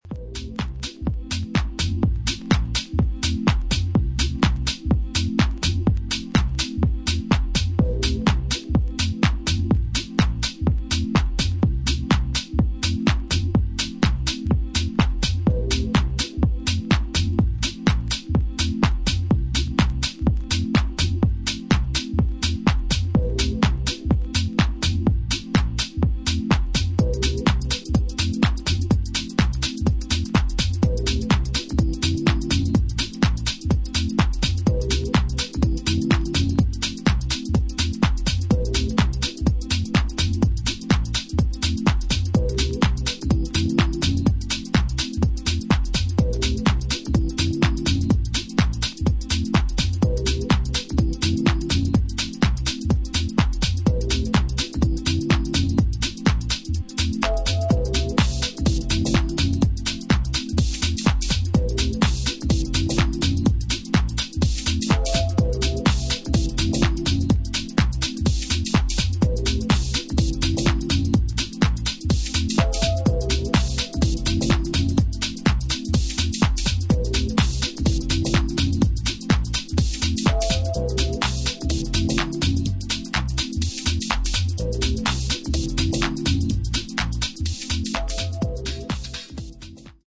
グルーヴを丁寧に刻んでいくテックハウス全4曲を収録